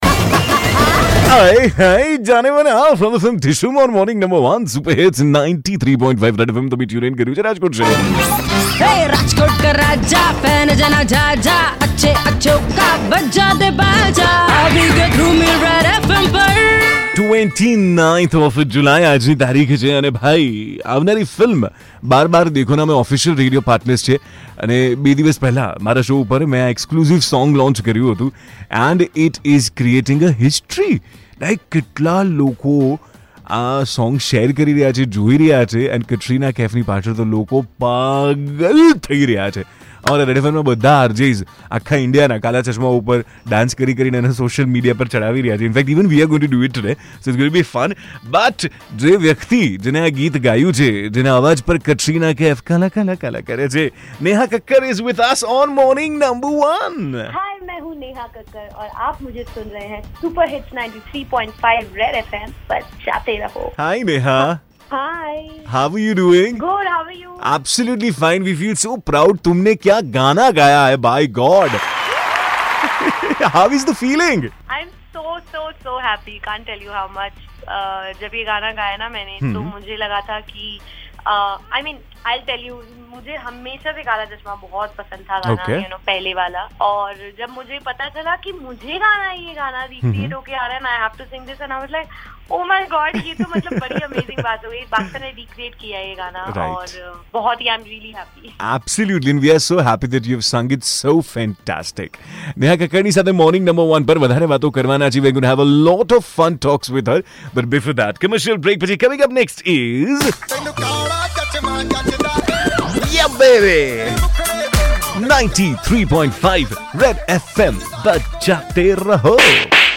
in conversation with NEHA KAKKAR for her brand new song KALA CHASHMA